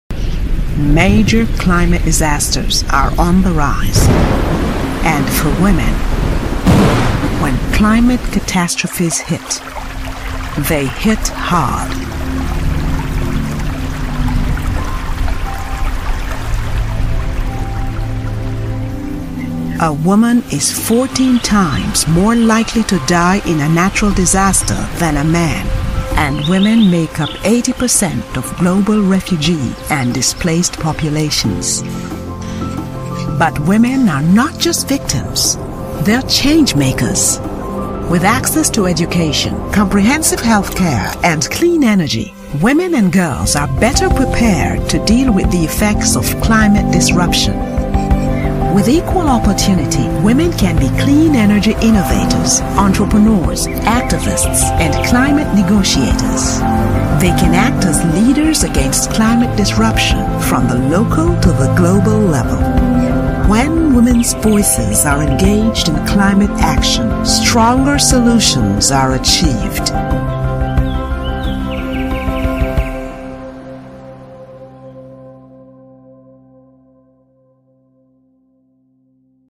guión de video
Guión de video en inglés